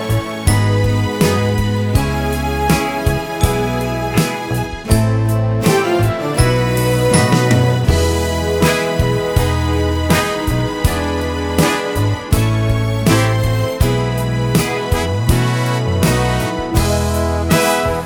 no Backing Vocals Soundtracks 1:35 Buy £1.50